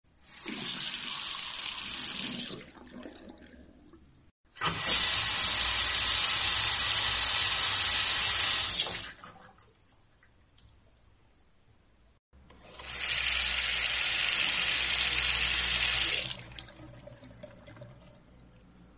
Theatre Project Water Tap